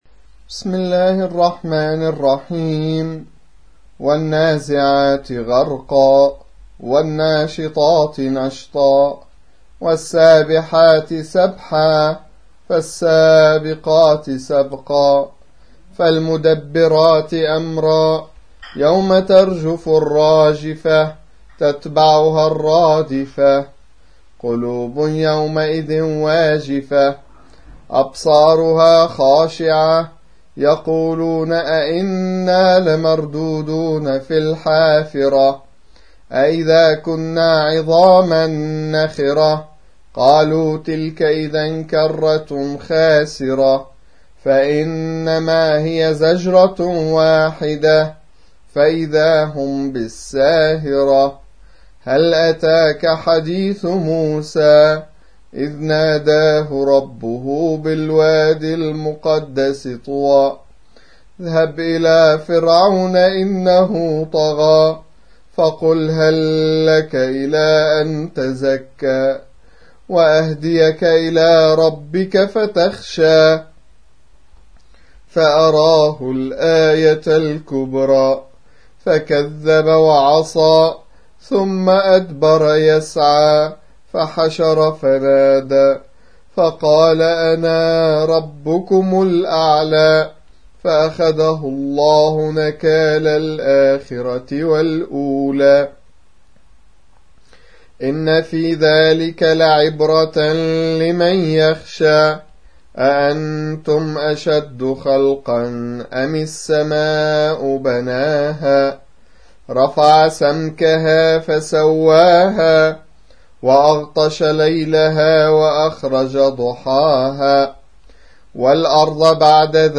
79. سورة النازعات / القارئ